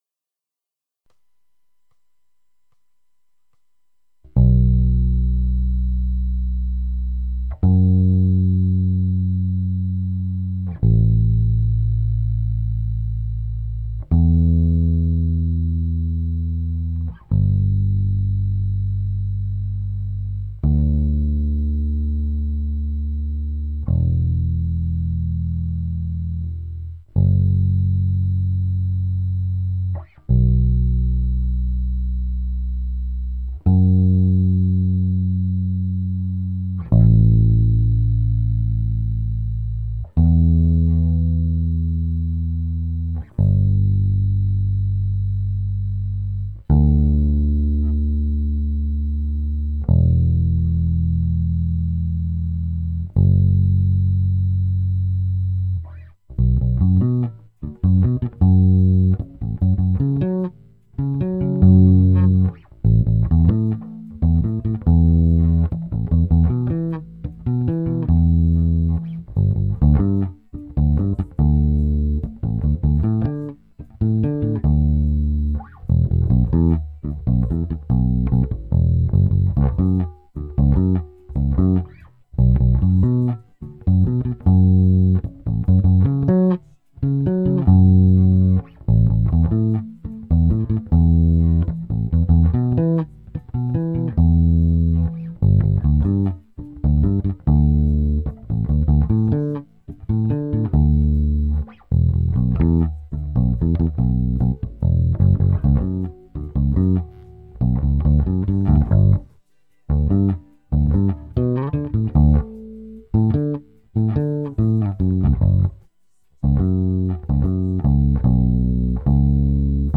basse uniquement